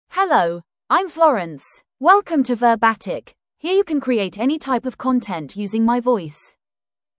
Florence — Female English (United Kingdom) AI Voice | TTS, Voice Cloning & Video | Verbatik AI
FemaleEnglish (United Kingdom)
Florence is a female AI voice for English (United Kingdom).
Voice sample
Florence delivers clear pronunciation with authentic United Kingdom English intonation, making your content sound professionally produced.